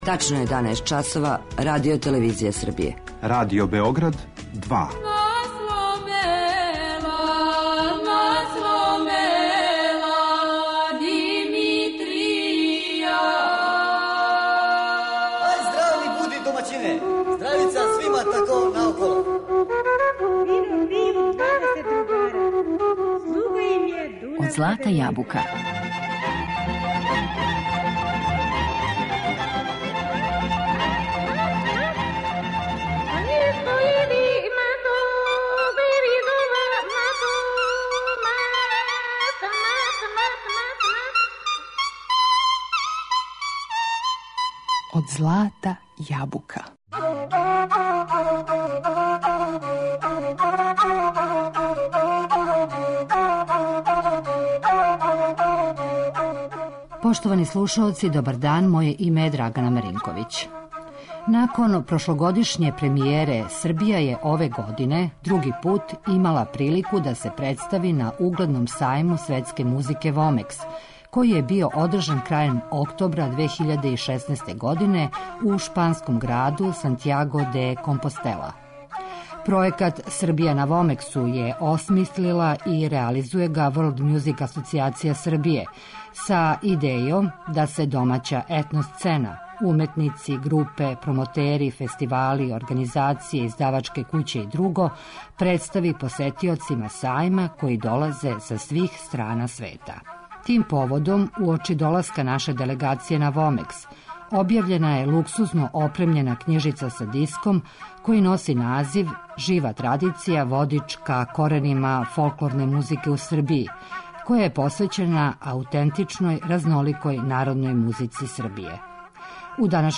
Овим пројектом престављена је свету аутентична и разнолика народна музика Србије, а ми ћемо данас слушати примере са овог музичког издања.